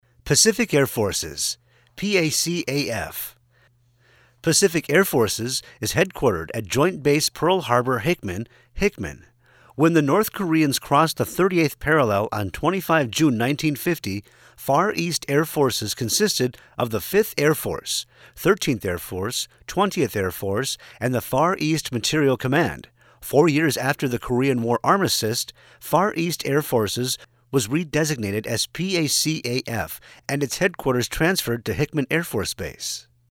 Male
Yng Adult (18-29), Adult (30-50)
My voice is best described as conversational, compelling and friendly. Can be comedic and sarcastic on the drop of a dime, yet overly- friendly and smooth at the same time.
E-Learning
Words that describe my voice are Strong, Fast, Smooth.